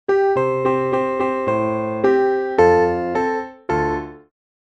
canciones infantiles
Partitura para piano, voz y guitarra.